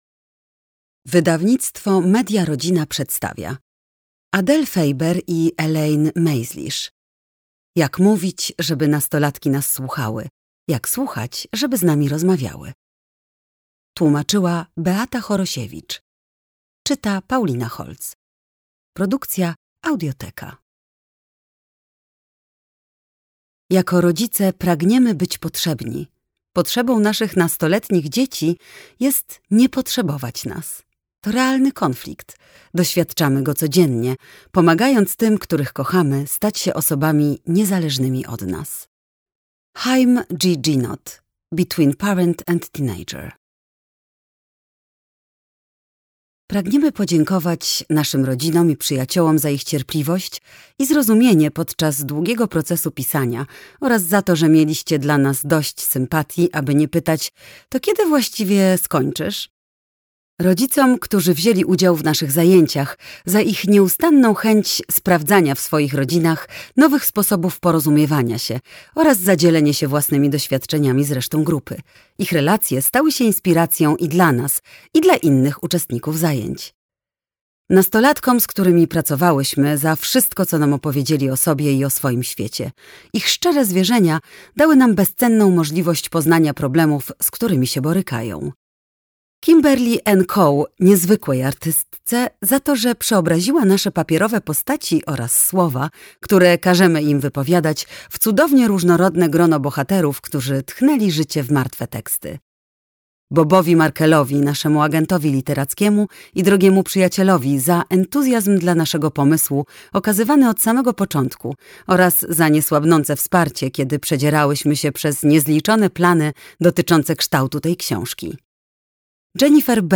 Jak mówić, ... Jak mówić do nastolatków, żeby nas słuchały mp3 - Adele Faber, Elaine Mazlish - audiobook